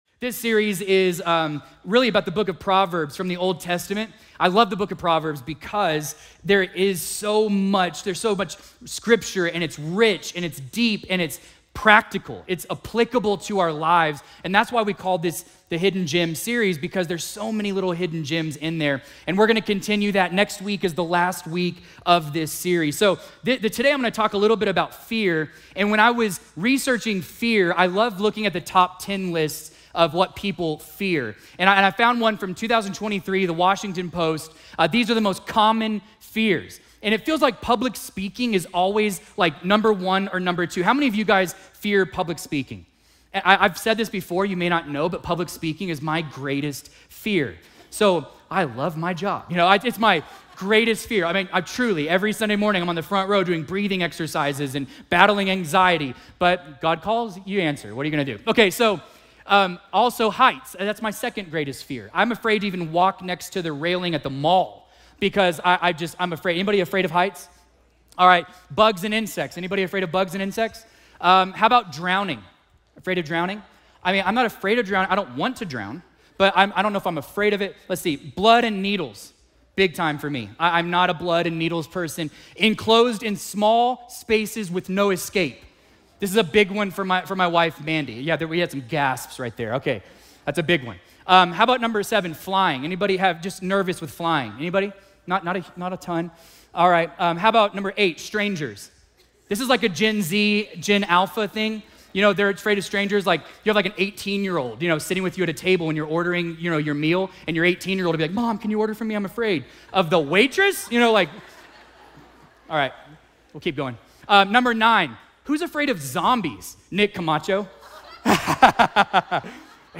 A message from the series "Homework." Join us in Week 4 of our 'Home Work' series as we explore the beauty and purpose of singleness!